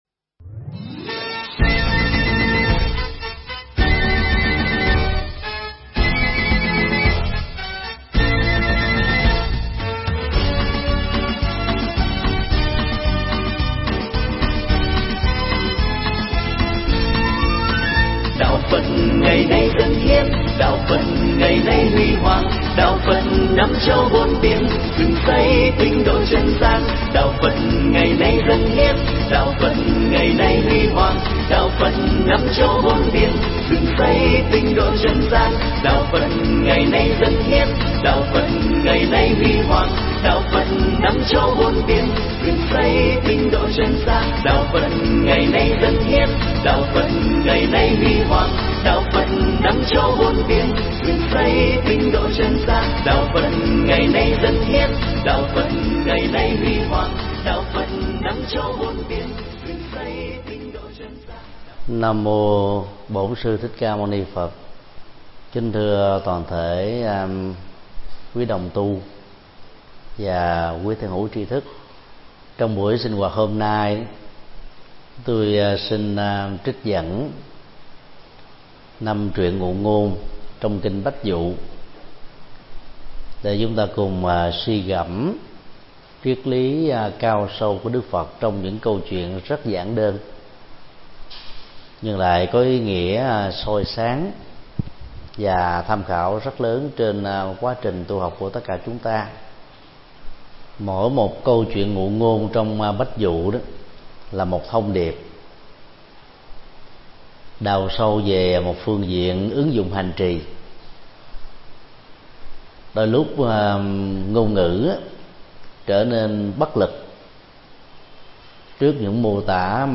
Mp3 Pháp Thoại Kinh Bách Dụ 09 (bài 38 – 42): Cắt đứt dòng nghiệp
giảng tại chùa Pháp Vân